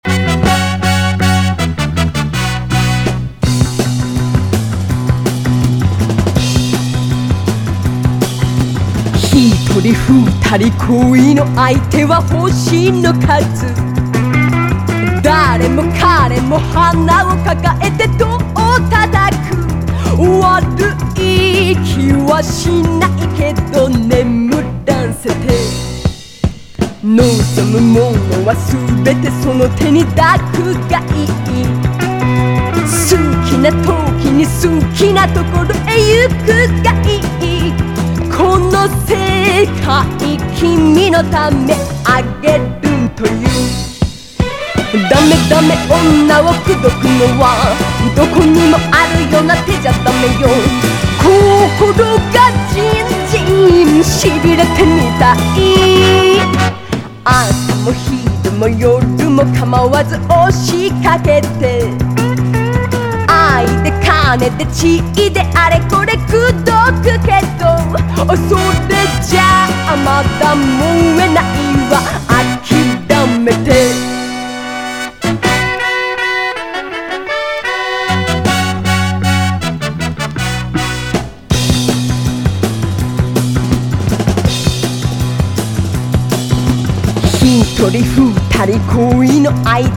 グルーヴ歌謡
久しぶりに聴くとこんなにカッコよかったっけ？と衝撃を受けてしまうパーカッシブなブレイク！
ブンブンとベースが唸りをあげるグルーヴ歌謡
メリハリの効いたパワフル・ヴォーカル、スリリングなリズム・サウンドは何度聴いても身体が反応してしまう